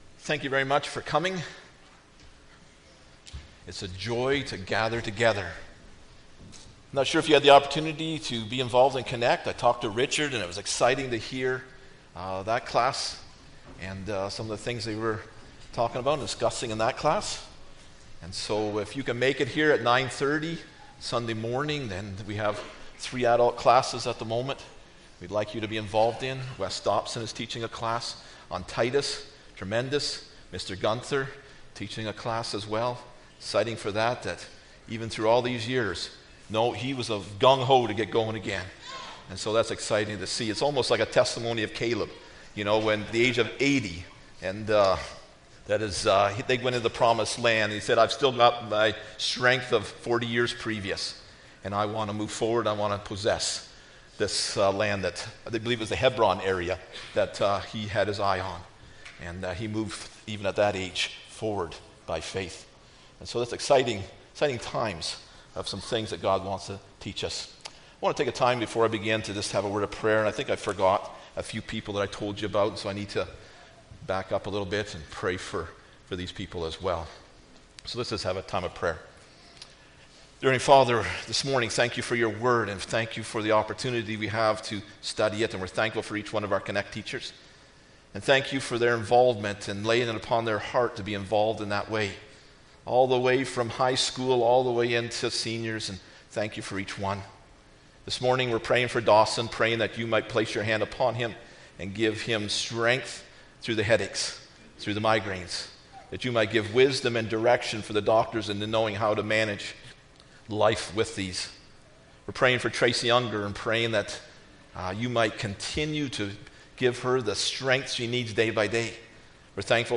Deuteronomy 1:19-27 Service Type: Sunday Morning Bible Text